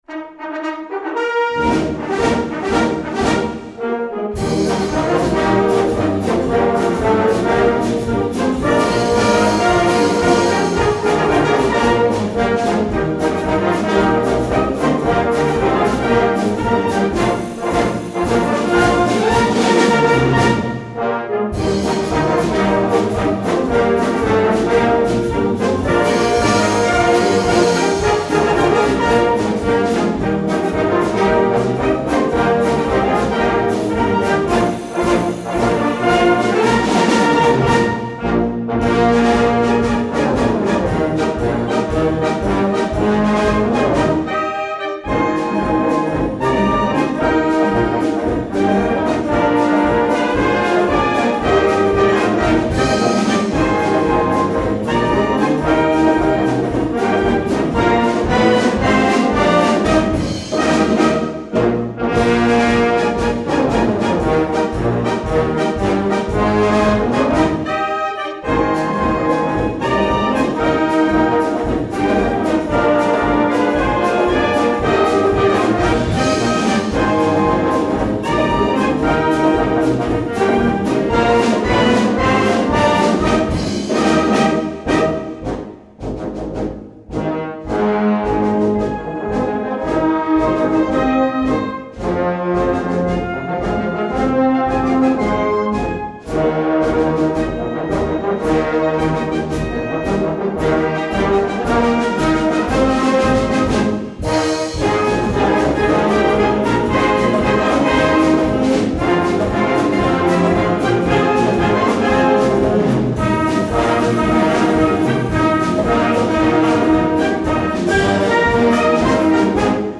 Gattung: Marsch-Swing
Besetzung: Blasorchester